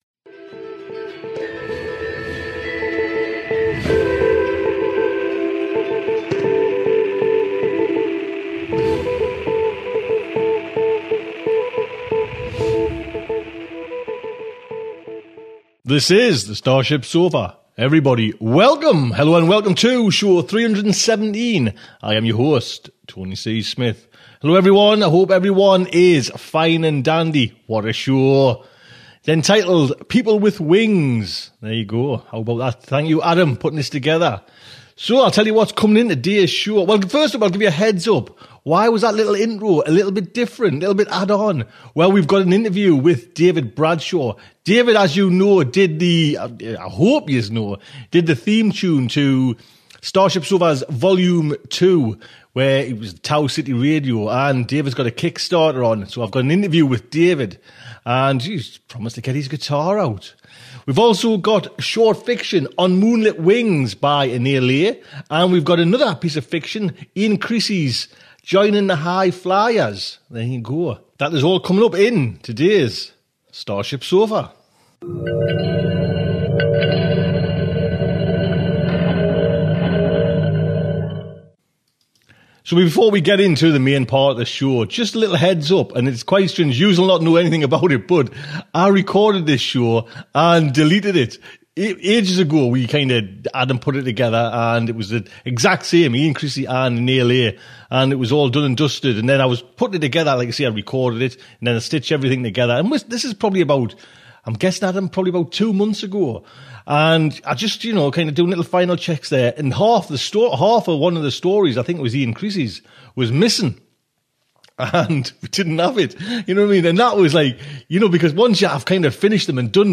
Coming up… People with wings! Short Fiction
Interview
Now he confines his acting to putting on silly voices in front of a microphone.